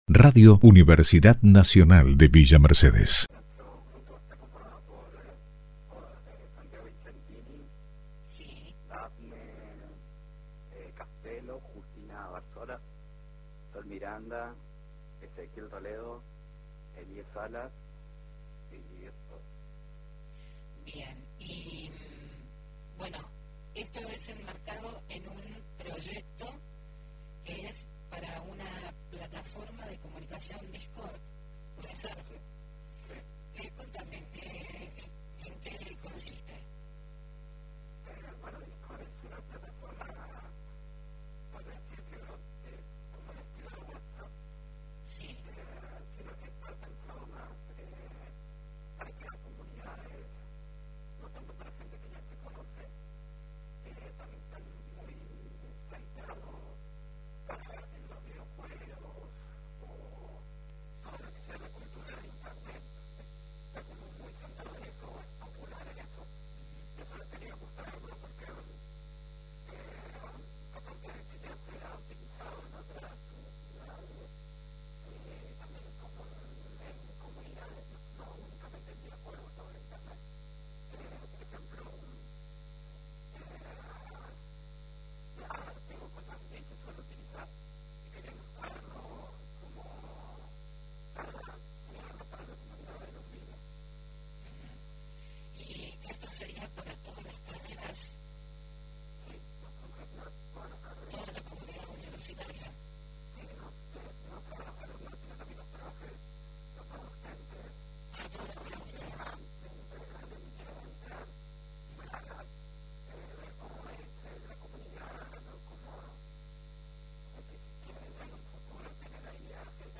dialogó con Agenda Universitaria y dio detalles de la propuesta